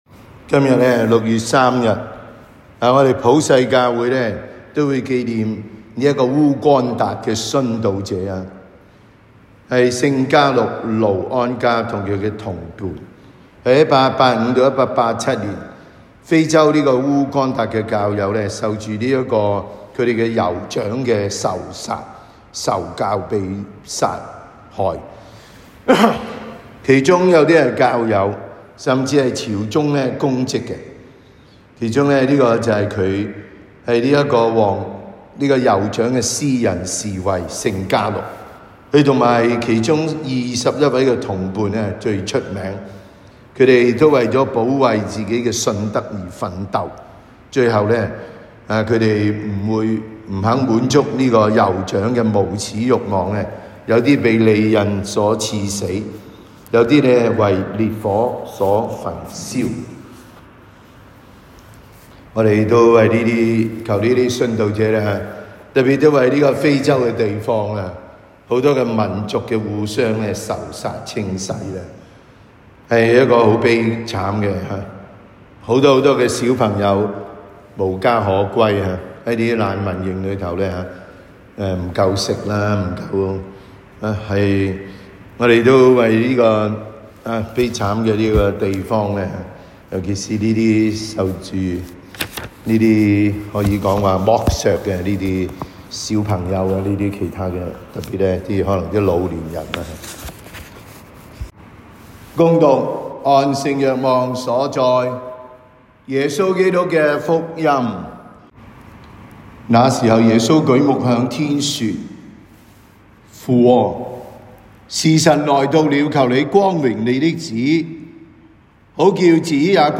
感恩祭講道